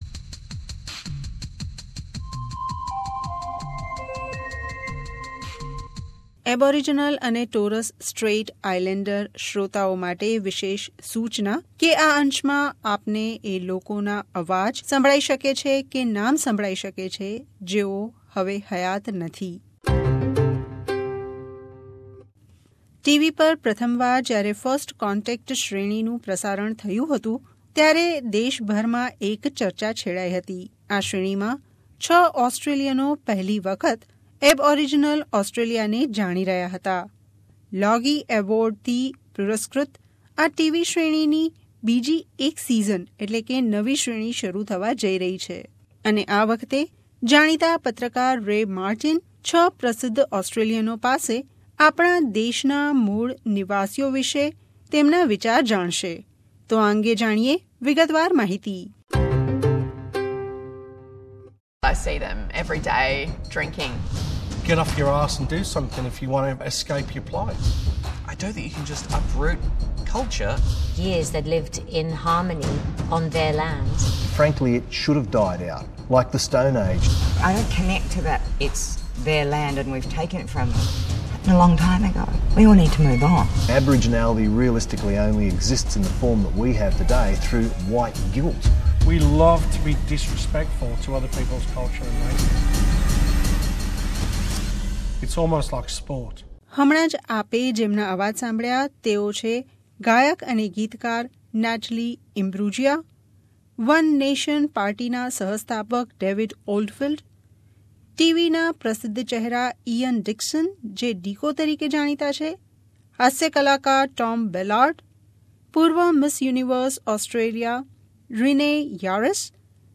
Listeners Warning - Aboriginal and Torres Strait Islander listeners are advised that this report may contain voices and names of the people who have died.